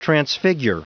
Prononciation du mot transfigure en anglais (fichier audio)
Prononciation du mot : transfigure